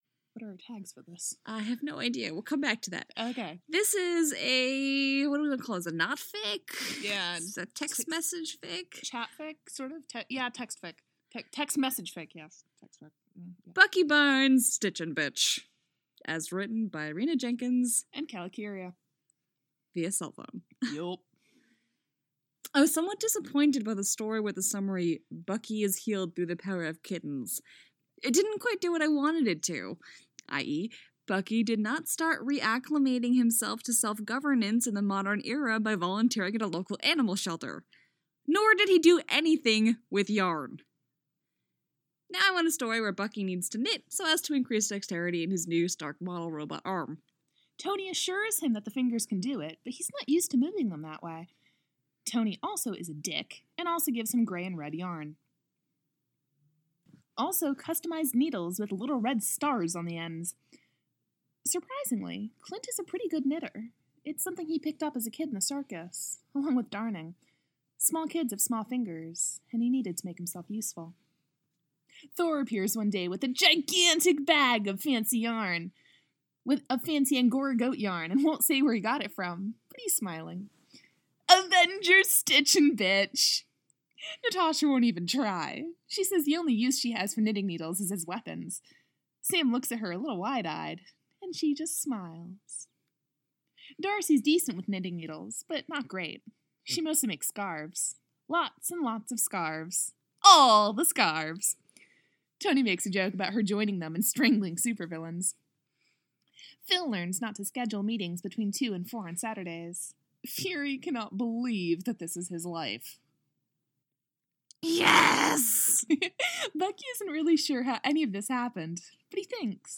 relationship: bilbo baggins/thorin oakenshield info: collaboration|ensemble , info|improvisational podfic